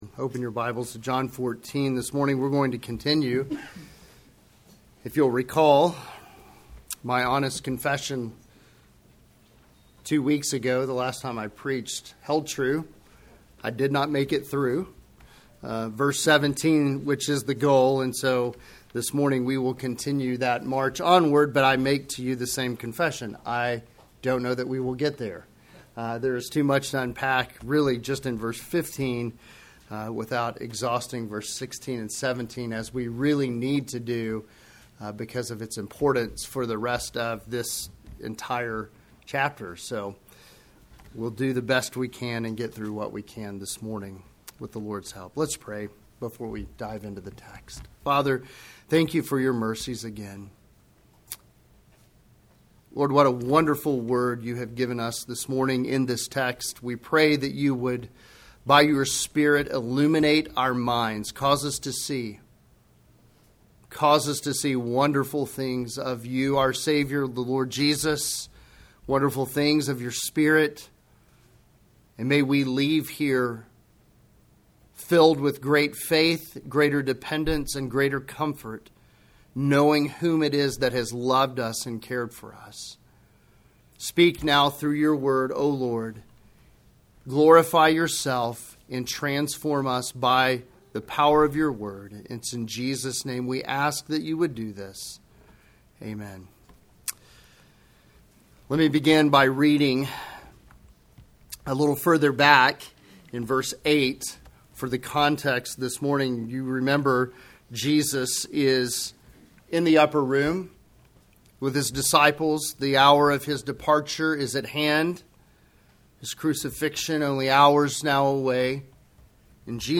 Sermons
sermon-august-3-2025.mp3